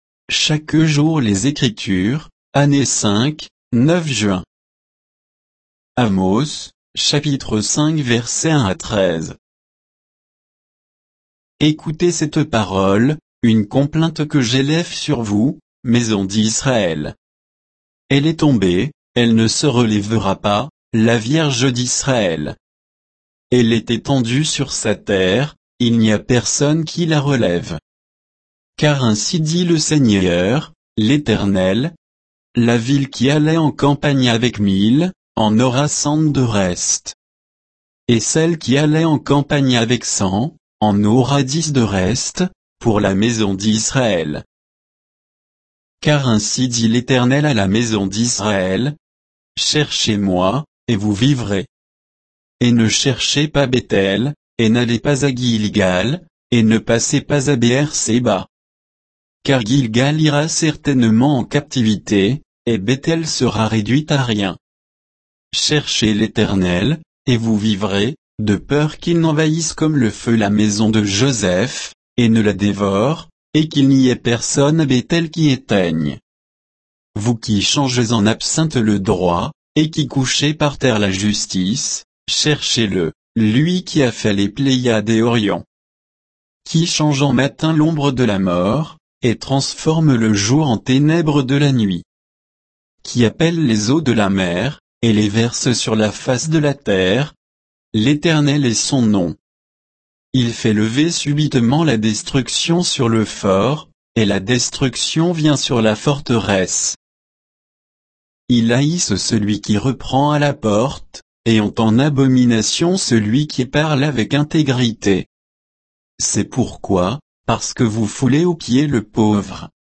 Méditation quoditienne de Chaque jour les Écritures sur Amos 5, 1 à 13